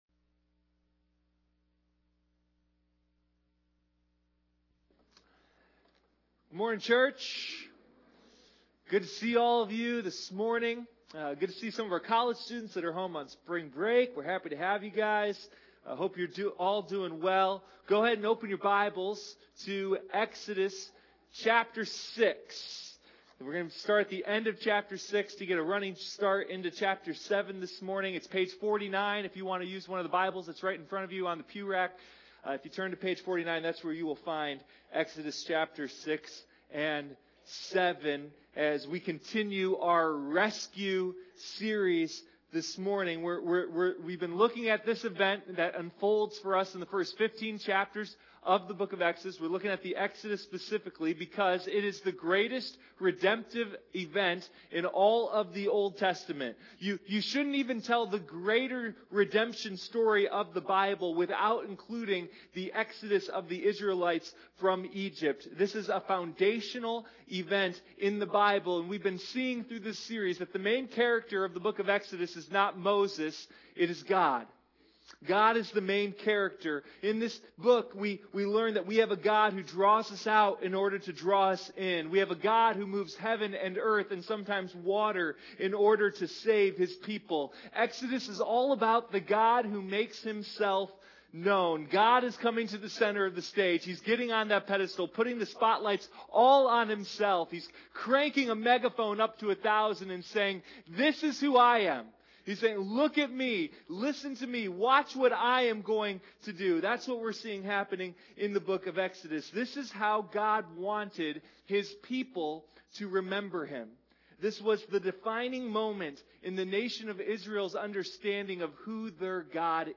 Sunday Morning Rescue: A Study of the Exodus